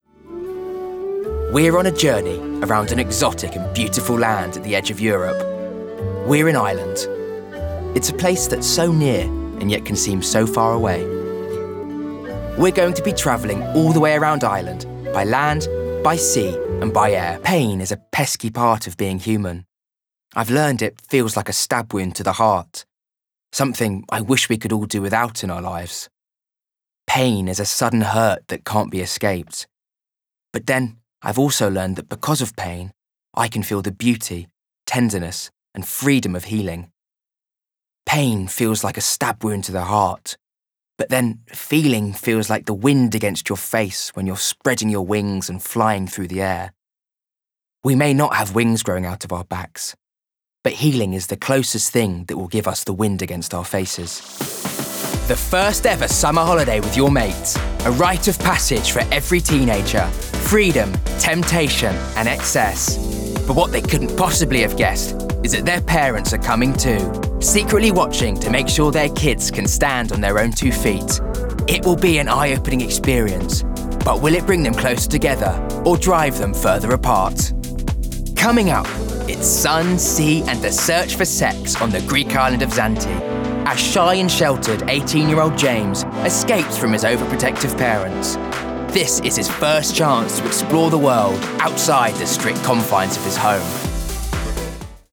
Documentary Reel
RP ('Received Pronunciation')
Documentary, Young, Natural, Relaxed, Engaging, Narration